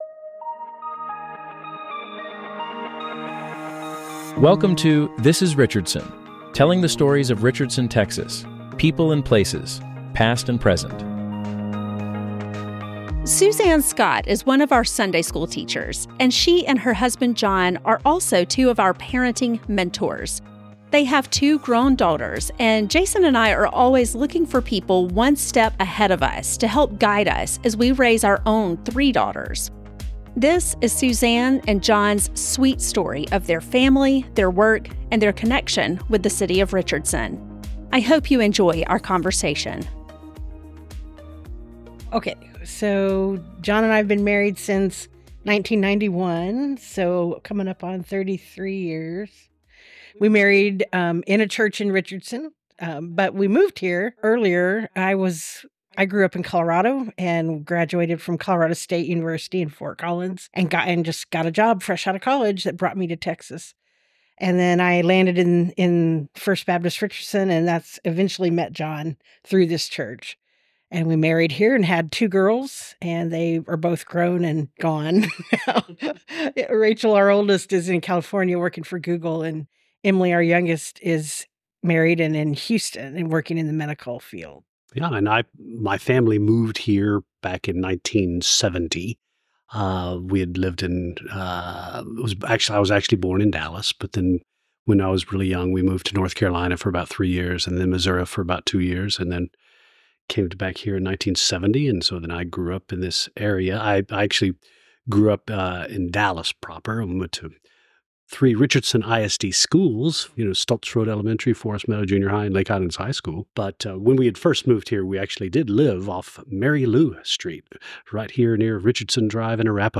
Our thanks to First Baptist Richardson for providing our recording space for this series.